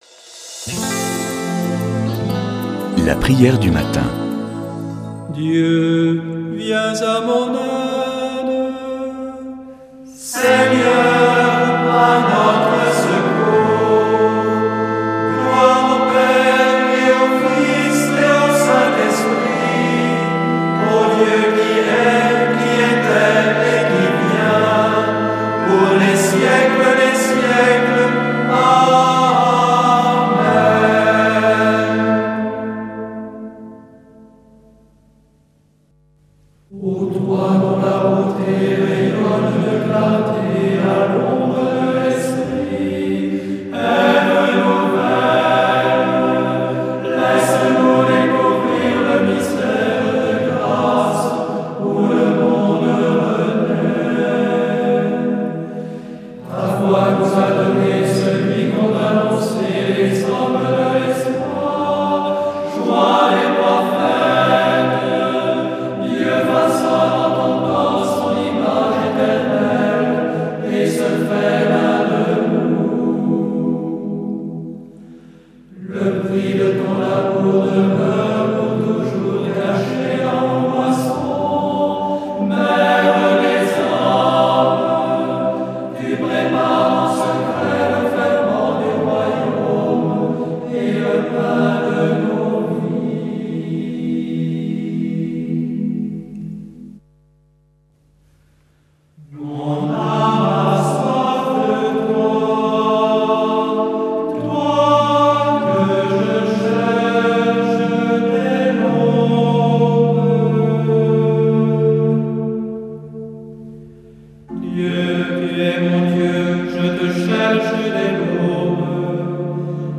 ABBAYE DE TAMIE